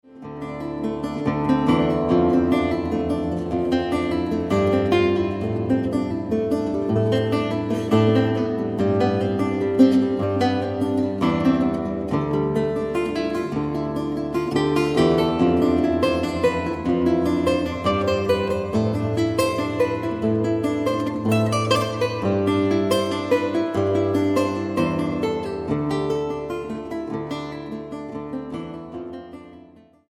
composer, lute & oud player from Japan
Contemporary , Lute